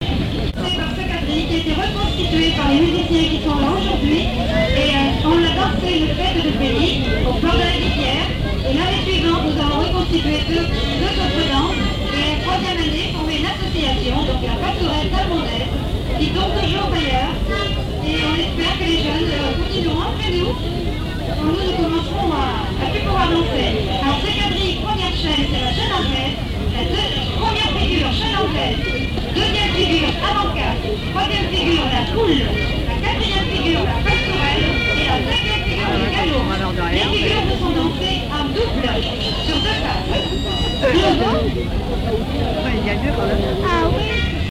danse (pratique de la)
lors d'une kermesse